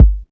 Kick [Exoticz].wav